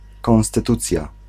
Ääntäminen
IPA : /ˌkɒnstɪˈtjuːʃən/